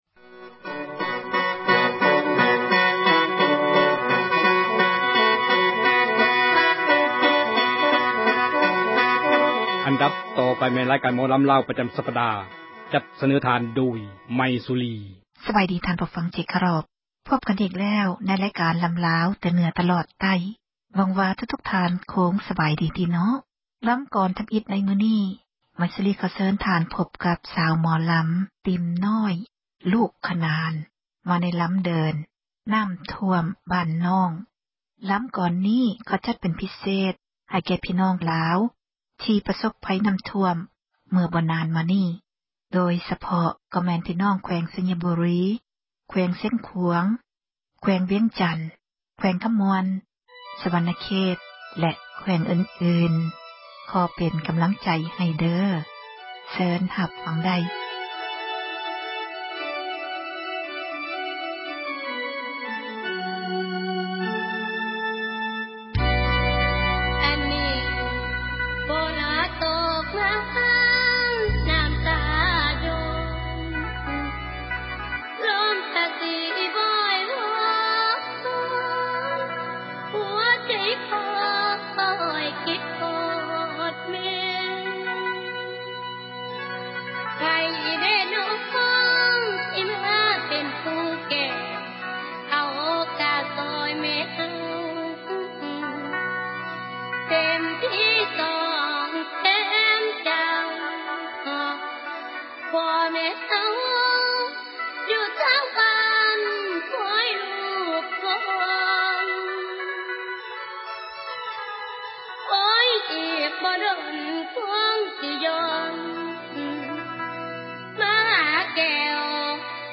ຣາຍການ ໝໍລຳລາວ ປະຈຳ ສັປດາ.